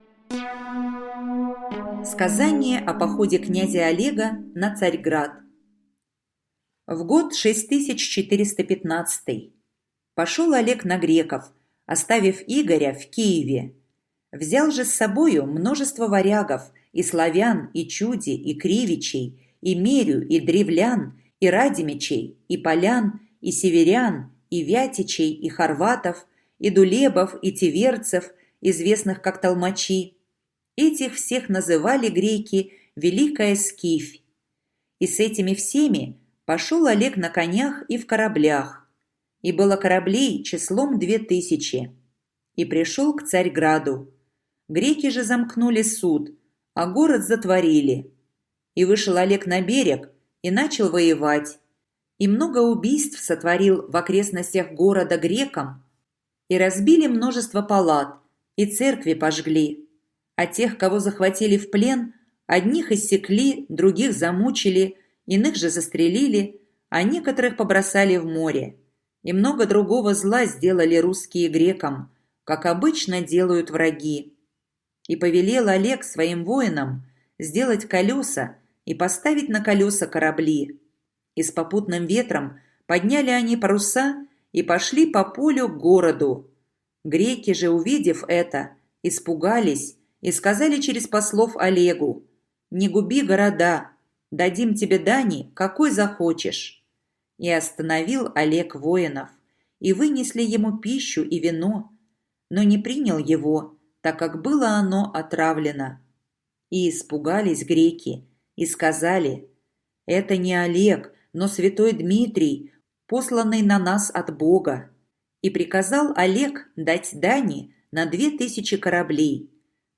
Сказание о походе князя Олега на Царьград - аудио предание | Мишкины книжки